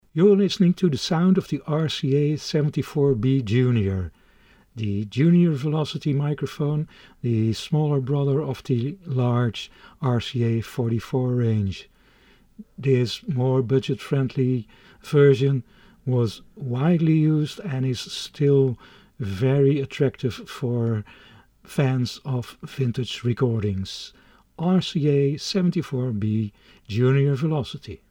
Although simpler in design than the 44BX, it had the same warm sound so characteristic of RCA ribbon microphones.
Sound of the 74B Jr